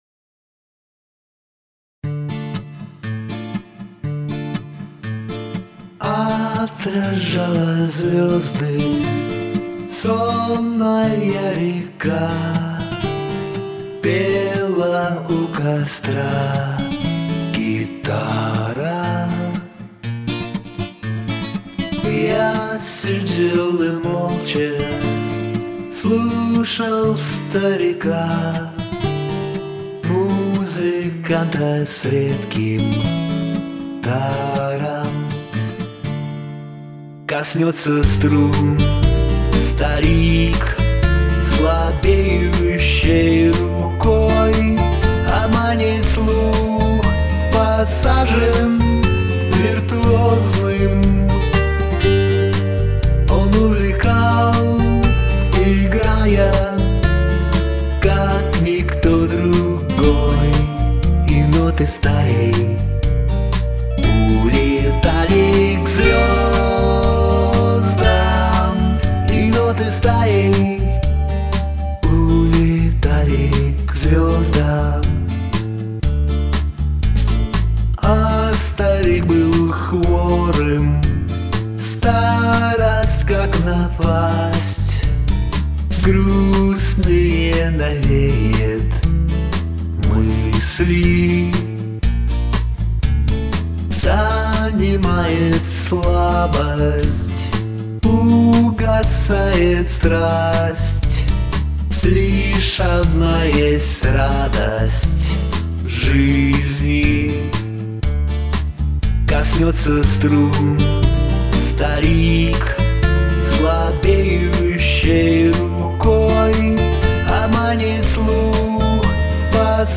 158 просмотров 170 прослушиваний 4 скачивания BPM: 110